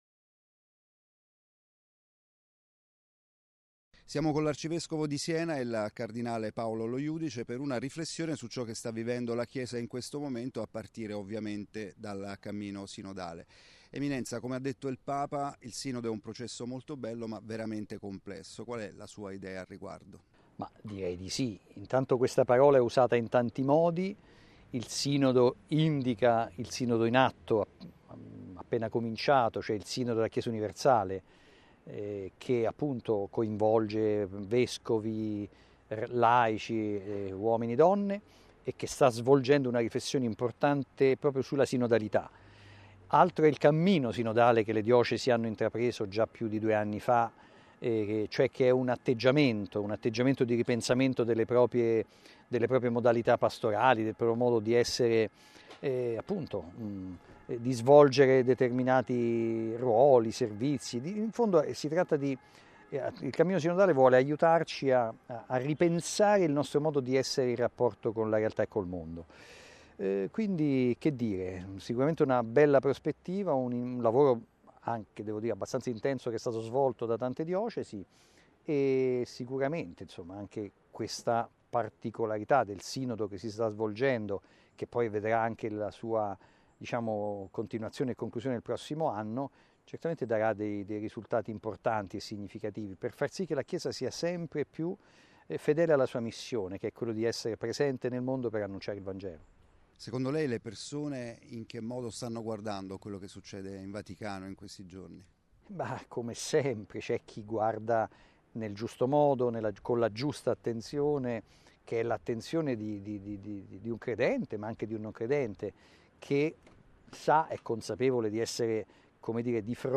Ne è convinto il cardinale Paolo Lojudice, arcivescovo di Siena-Colle di Val D’Elsa-Montalcino e vescovo di Montepulciano-Chiusi-Pienza, che in un colloquio con Telepace ripreso da Vaticanews riflette sulle sfide sul tavolo a partire dal Sinodo.